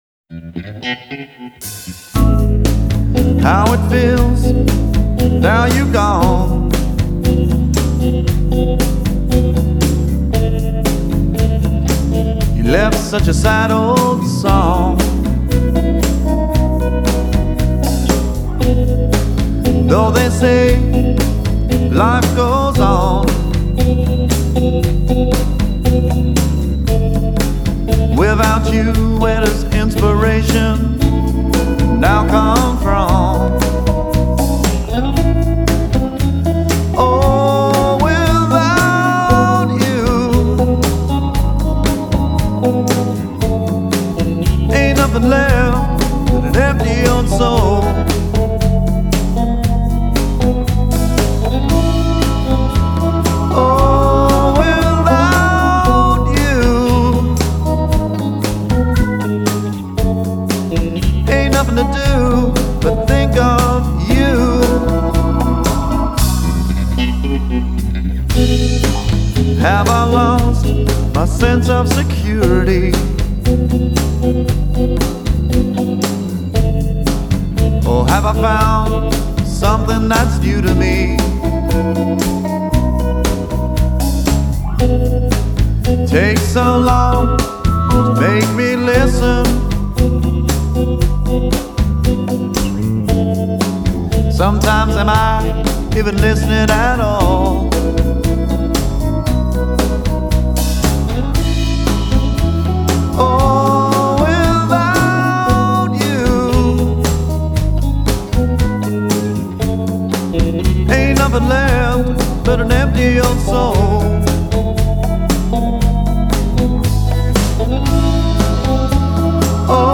offers up a gutsy, yet classy sound